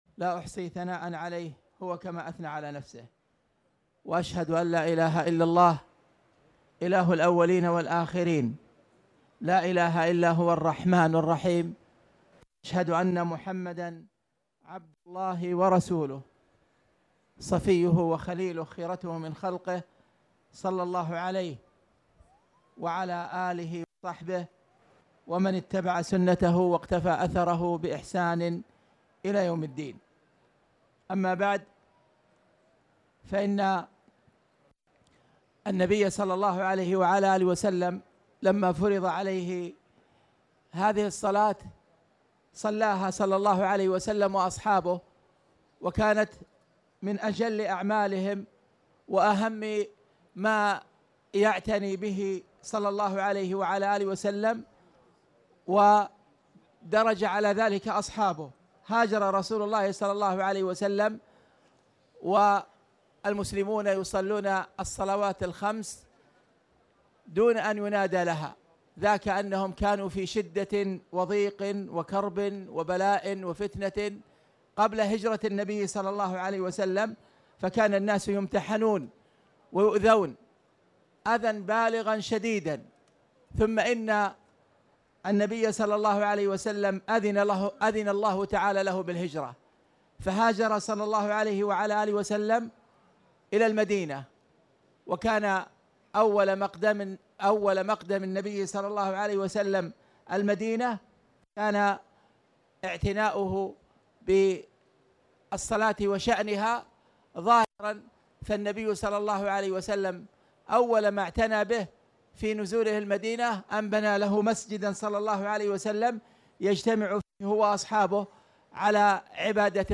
تاريخ النشر ١٣ صفر ١٤٣٨ هـ المكان: المسجد الحرام الشيخ: فضيلة الشيخ أ.د. خالد بن عبدالله المصلح فضيلة الشيخ أ.د. خالد بن عبدالله المصلح كتاب الصلاة-باب الأذان